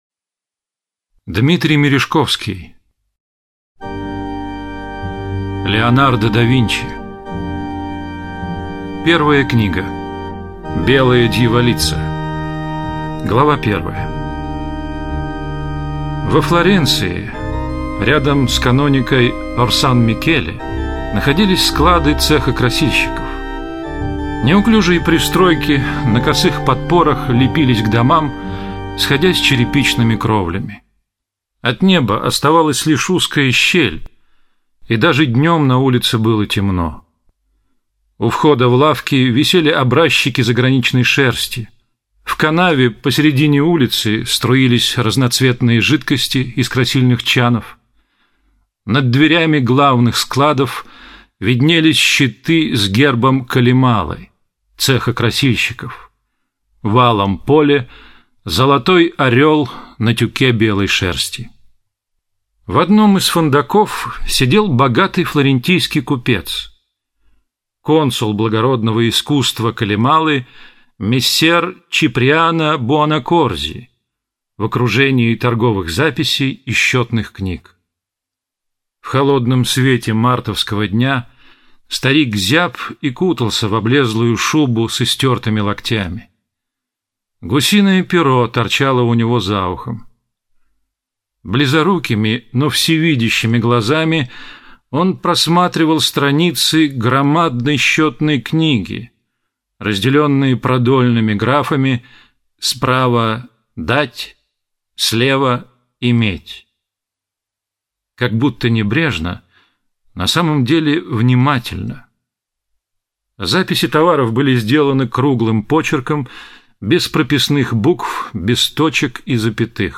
Аудиокнига Воскресшие боги Леонардо да Винчи | Библиотека аудиокниг